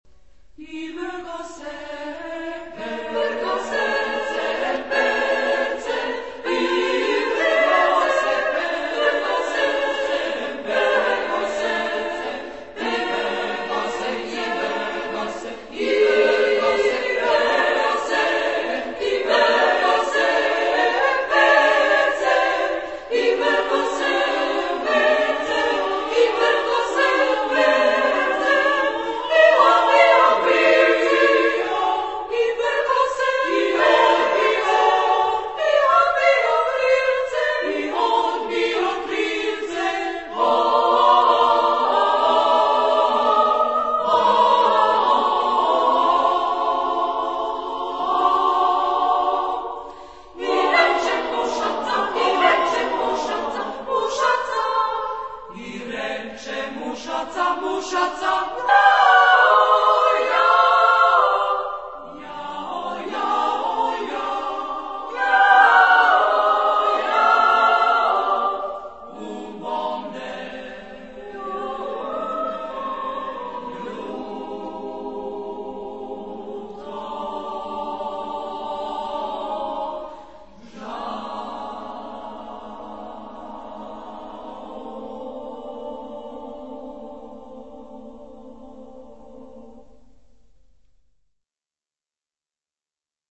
Carácter de la pieza : humorístico ; moderado
Tipo de formación coral: SSAA  (4 voces Coro femenino )
Solistas : Sopranos (2)  (2 solista(s) )
Tonalidad : polimodal ; atonal